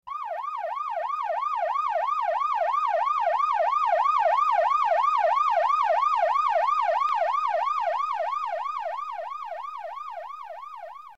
PLAY sirena de policia mp3
sirena-de-policia.mp3